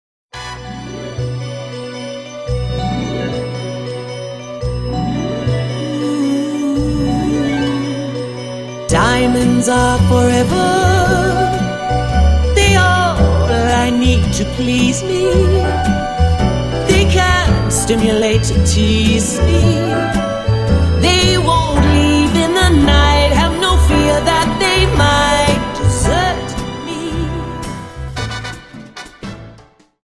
Dance: Slowfox